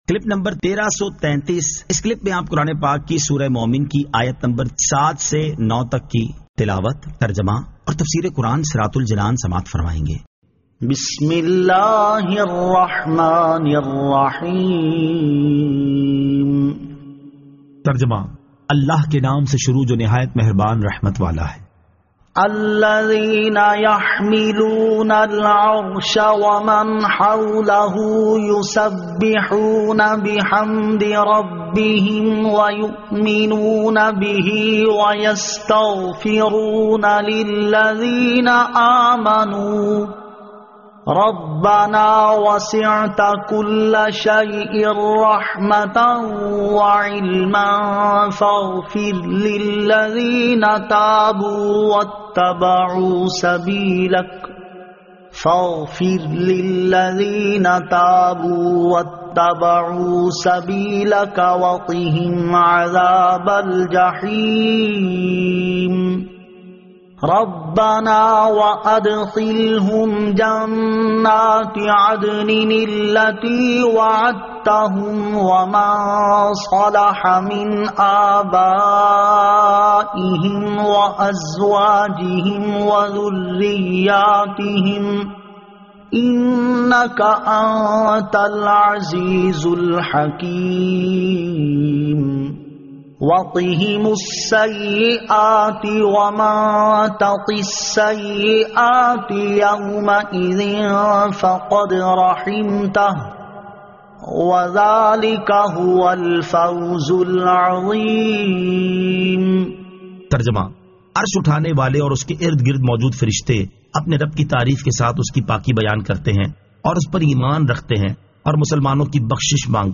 Surah Al-Mu'min 07 To 09 Tilawat , Tarjama , Tafseer